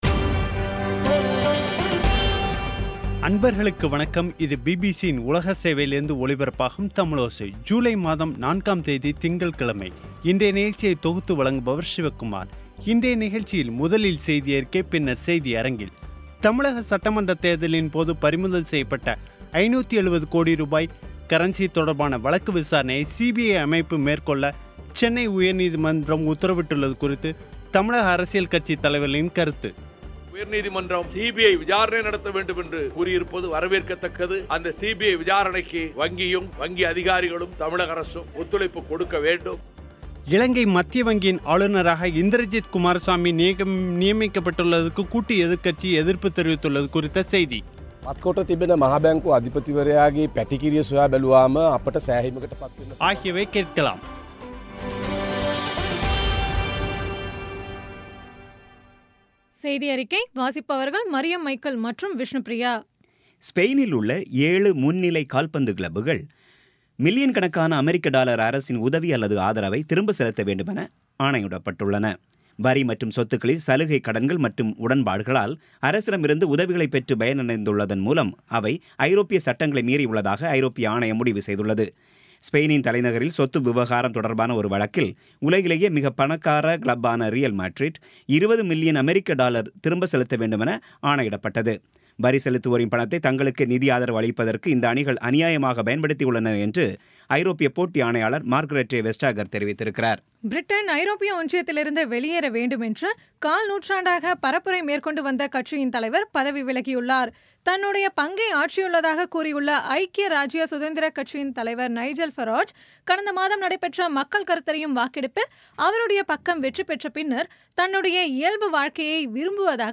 இன்றைய நிகழ்ச்சியில் முதலில் செய்தியறிக்கை, பின்னர் செய்தியரங்கில்